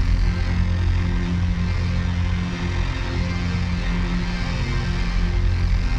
DD_LoopDrone3-F.wav